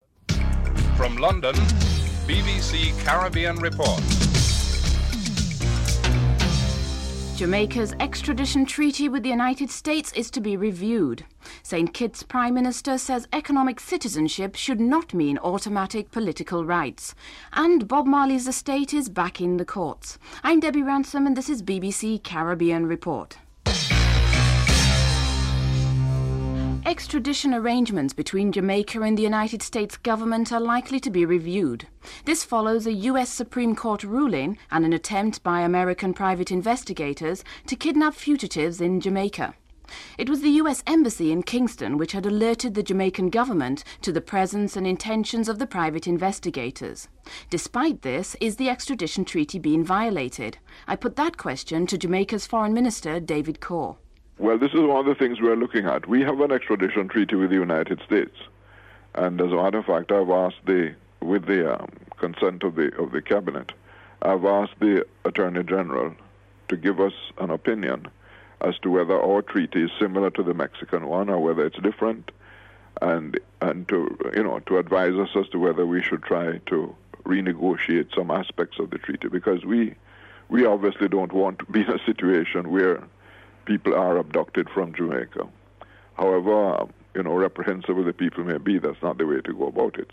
1. Headlines (00:00-00:25)
2. Jamaica’s Foreign Minister, David Coore comments on the violation of the extradition treaty between Jamaica and the US following a US Supreme Court ruling and an attempt by American private investigators to kidnap fugitives in Jamaica (00:26-02:52)
4. Dr. Kennedy Simmonds, Prime Minister of St. Kitts and Nevis discusses the rights of economic citizens under the economic citizenship program in his country (04:48-08:56)